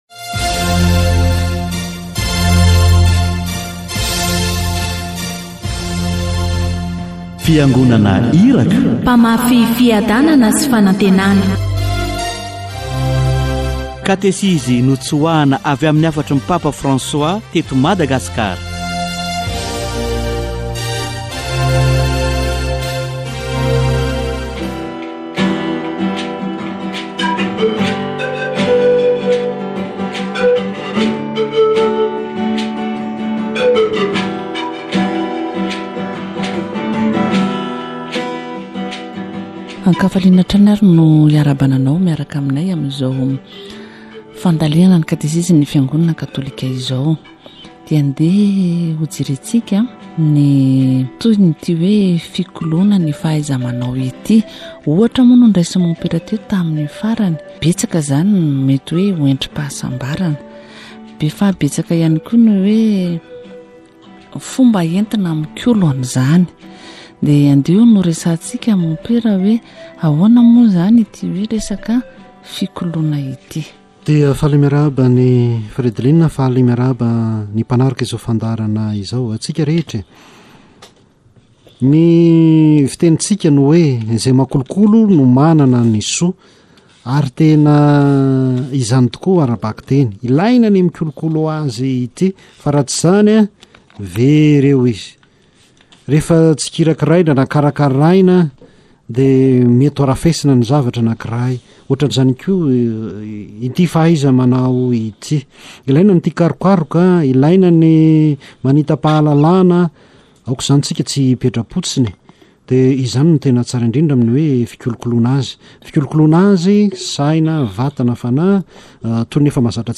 Catechesis on skills development